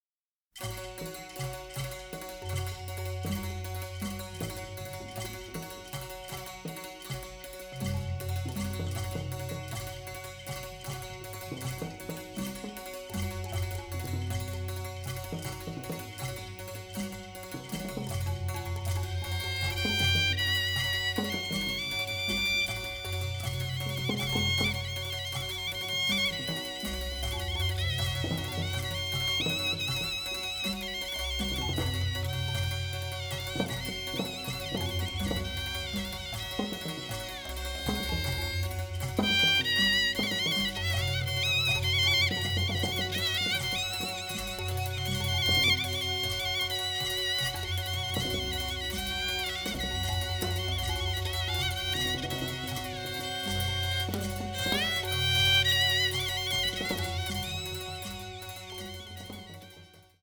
classic war score